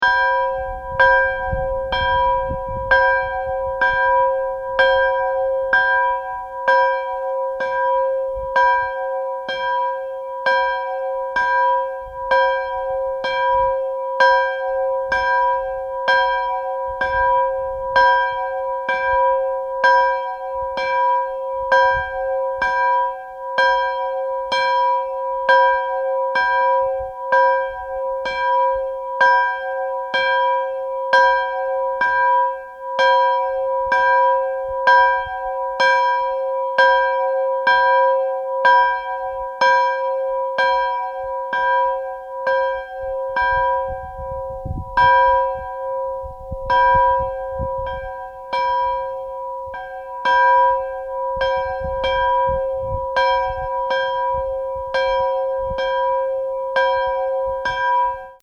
It’s not very often that one can find a quiet place, and except for the occasional car and constant wind, we were pretty excited. We stopped by a solid waste transfer station in a little valley that was not at all busy on a Saturday afternoon and took turns ringing and recording the bell- Up close, down the road, in the distance down the hill behind a tree.
Near the transfer station
Anyway, as nice as the place was, it was less than ideal because the sound from the few cars that did pass lingered for a long time.